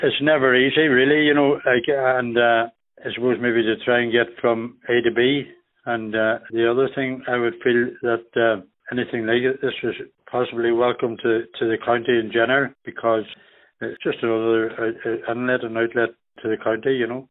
Donegal Municipal District Councillor Tom Conaghan says the flights are important, given the lack of transport options in the county: